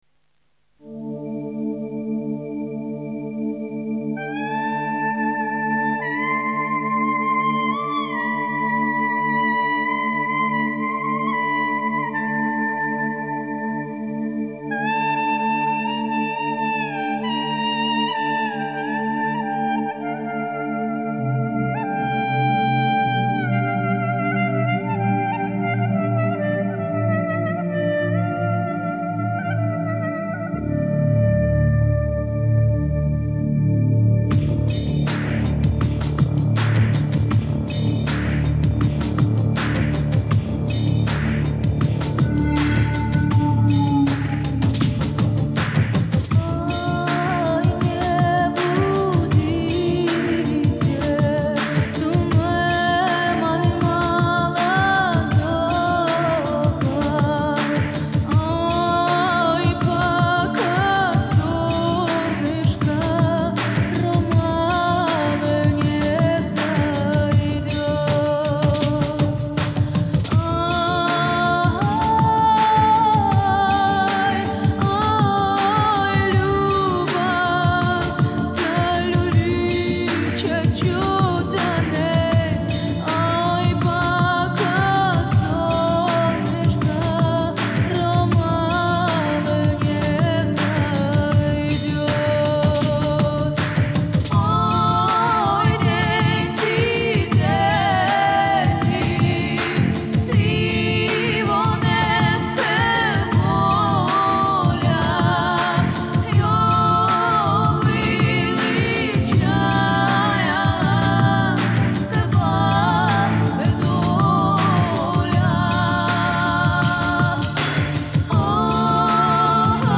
krasivaya_pesnya_cyganskaya__oy_ne_budite.mp3